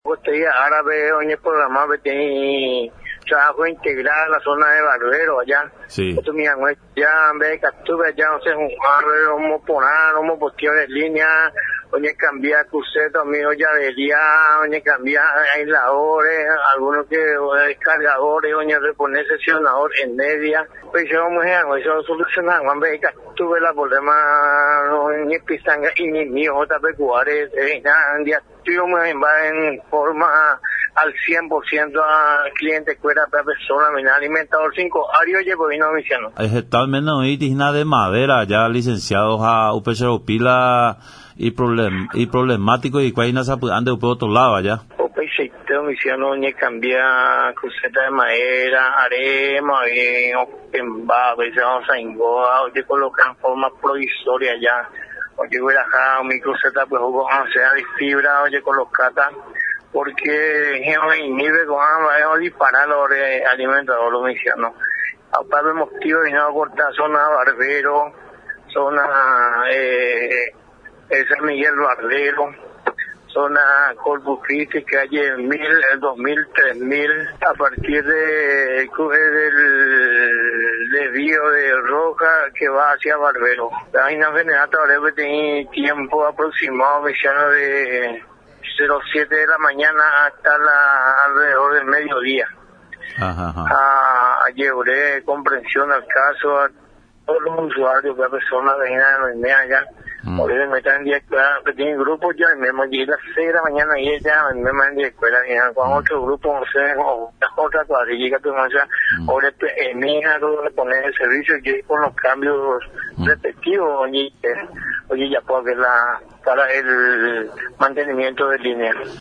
en conversación con Radio Nacional